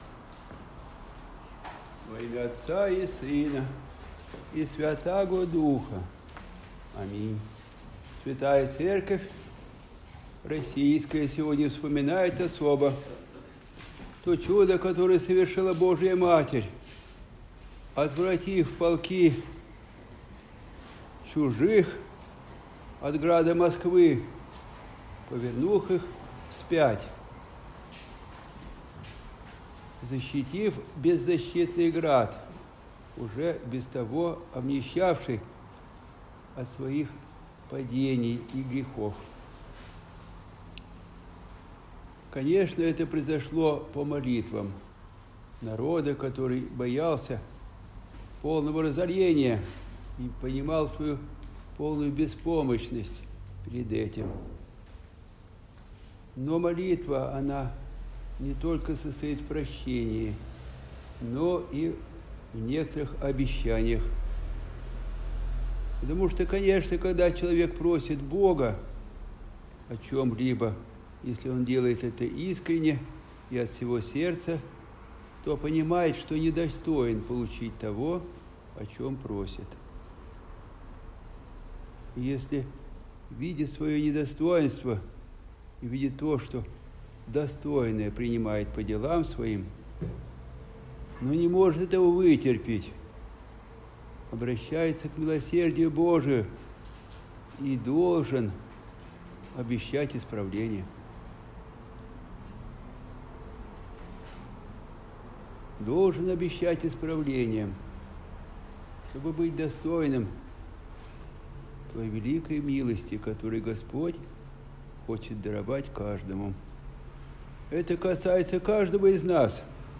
Проповедь
в день празднования Владимирской иконе Божией Матери